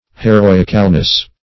Meaning of heroicalness. heroicalness synonyms, pronunciation, spelling and more from Free Dictionary.